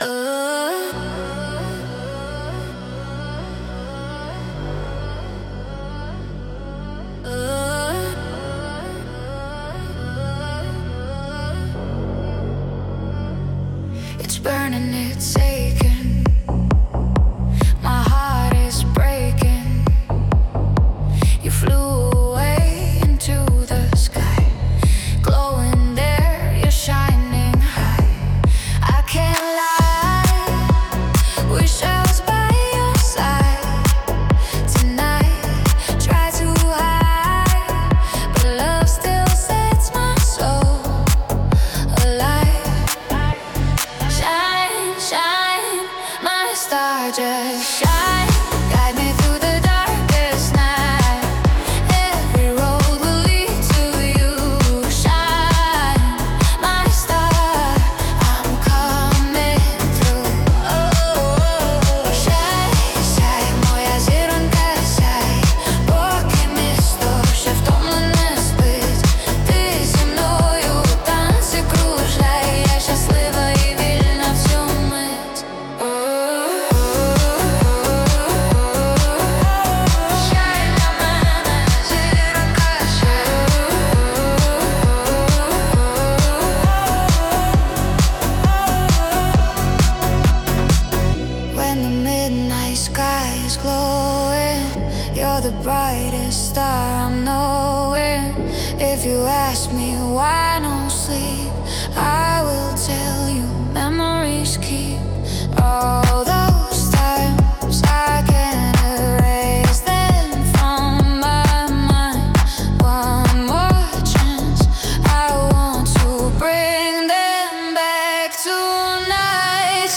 Музика та голос =SUNO
СТИЛЬОВІ ЖАНРИ: Ліричний
ВИД ТВОРУ: Пісня